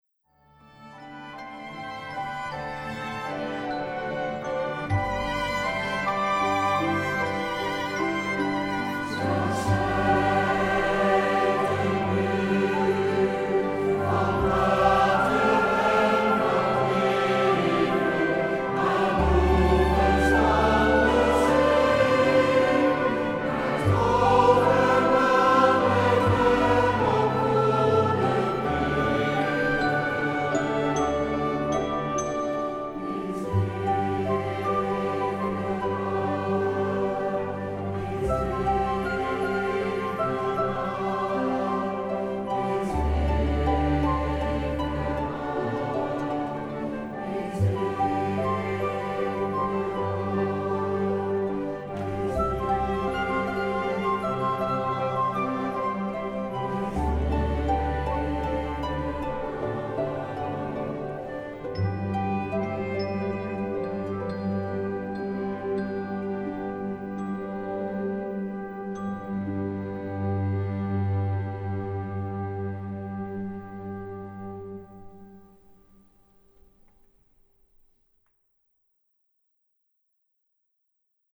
Oratorium over het leven van Mozes